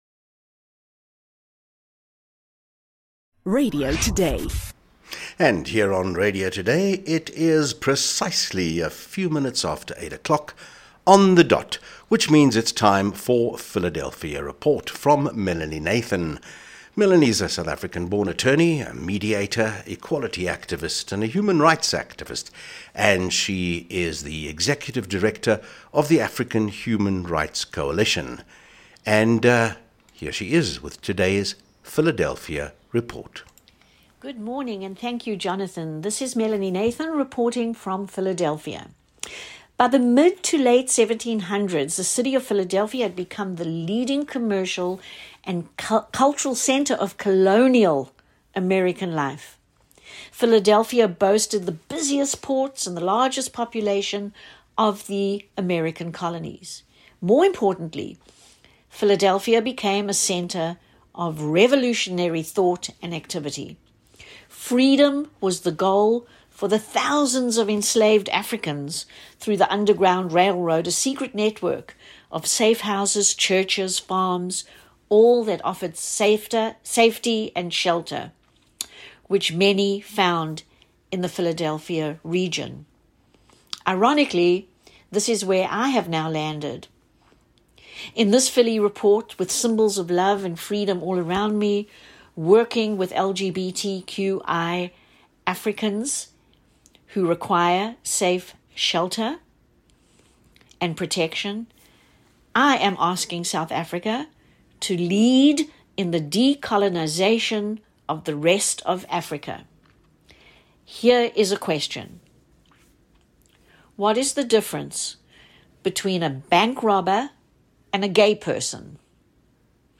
MUSIC – Philadelphia Freedom, Elton John.